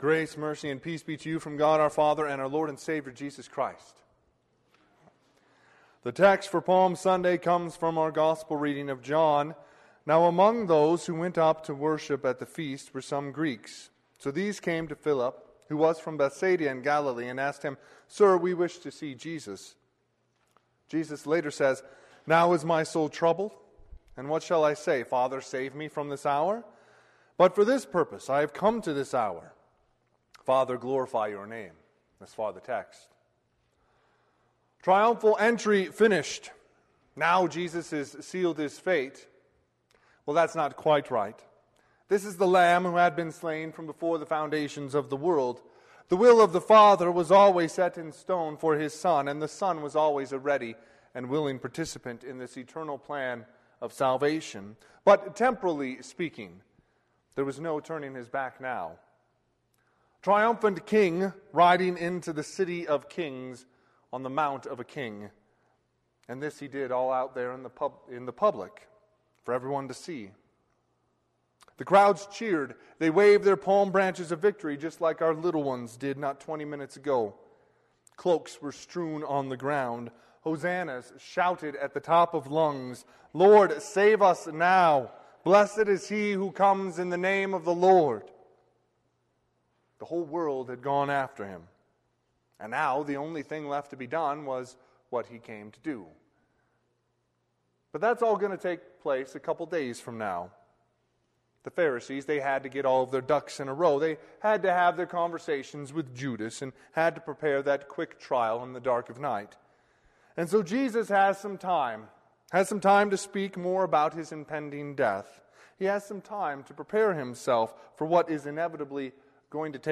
Palm Sunday Sermon - 3/28/2021 - Wheat Ridge Lutheran Church, Wheat Ridge, Colorado